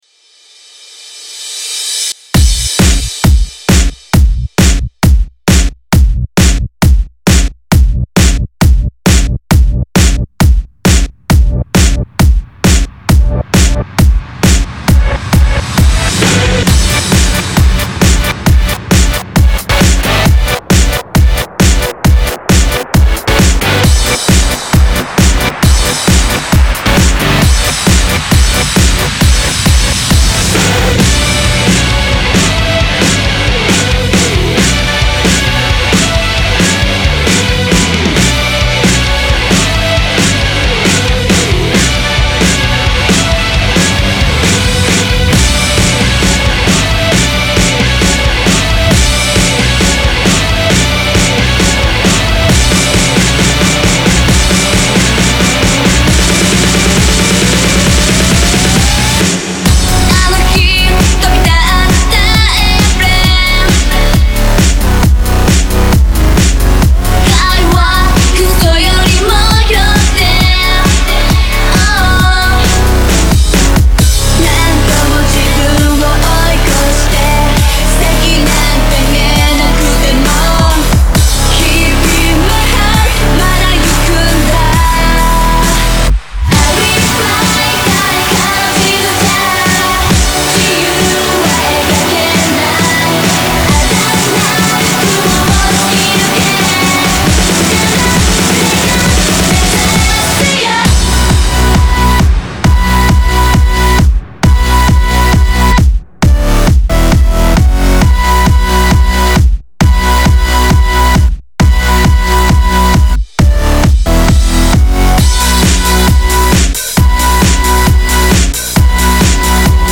Genre(s): Electro